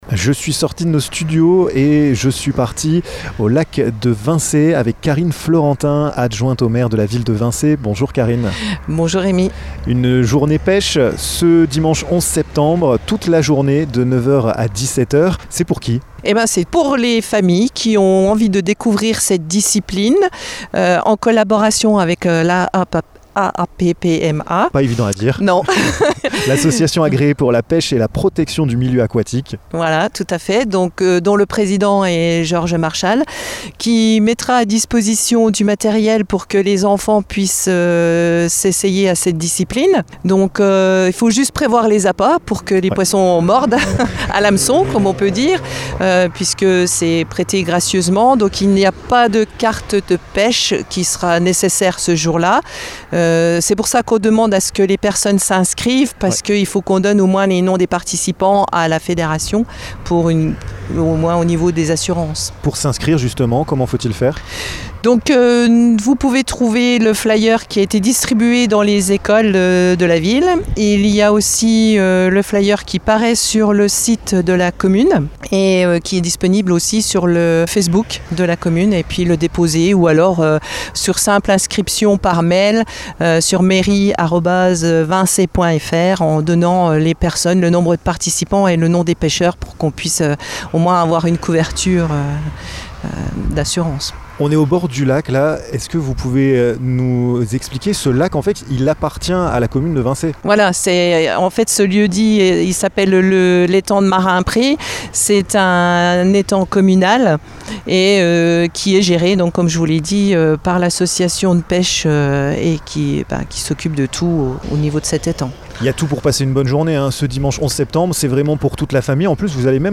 Karine Fleurentin, adjointe à la ville de Vincey, vous en dit plus dans ce podcast.